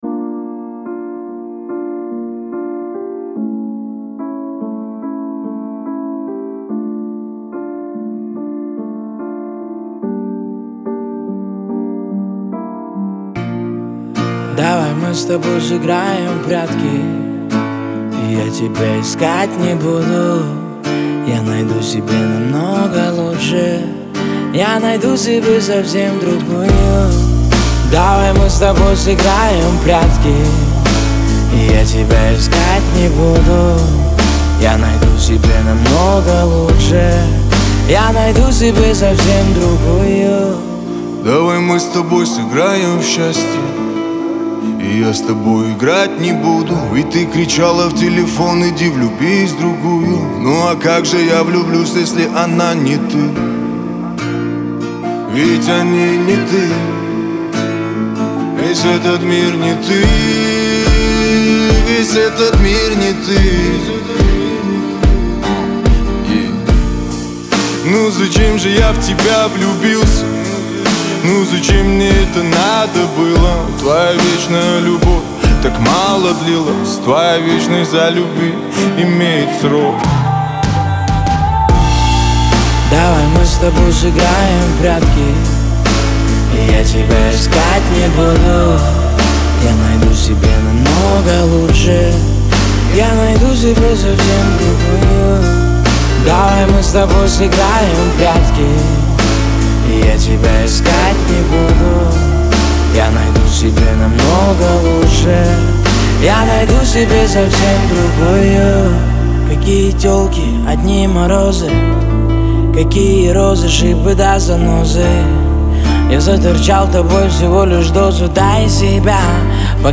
Медляки
русские медляки
медленные песни Размер файла